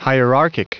Prononciation du mot hierarchic en anglais (fichier audio)
hierarchic.wav